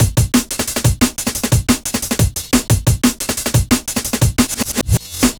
cw_amen18_178.wav